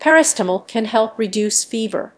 tutorials / tts / audio_samples